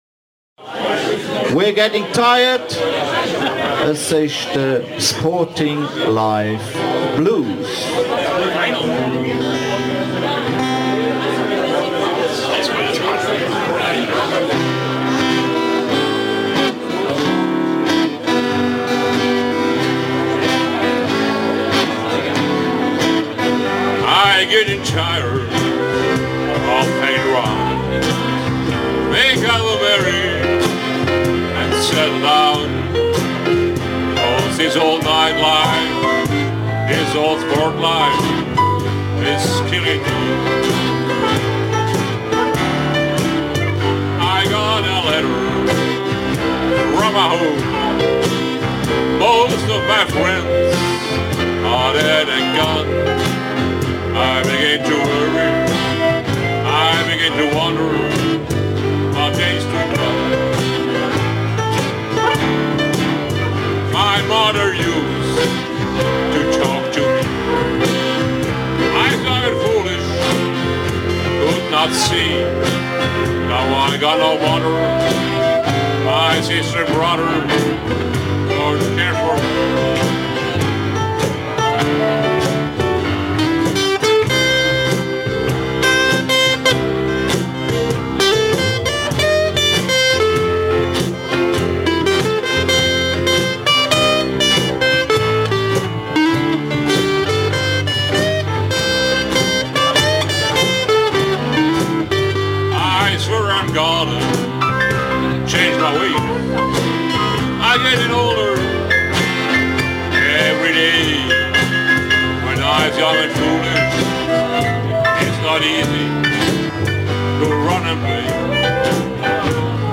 Luzerner Blues Band sucht Drummer Luzern
2 Guitar, 1 Bass, 1 Harps, 1 Kbd.